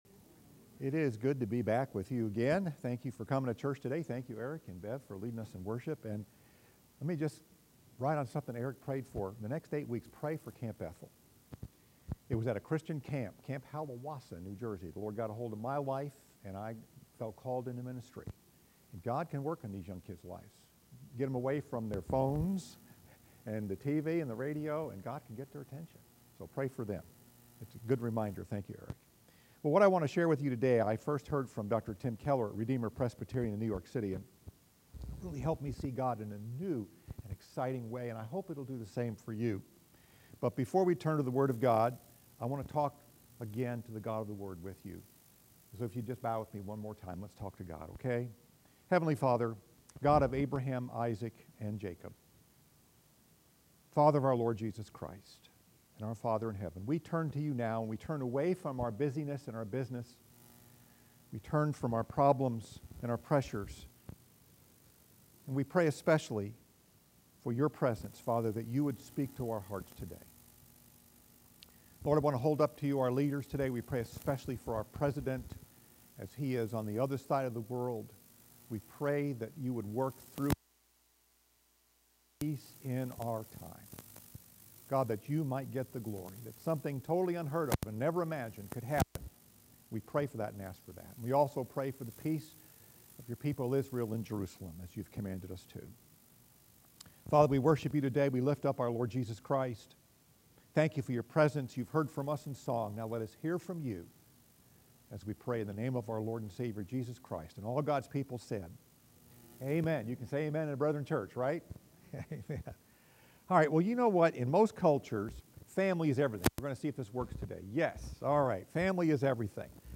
Message: The Girl No One Wanted Scripture: Genesis 29:14-35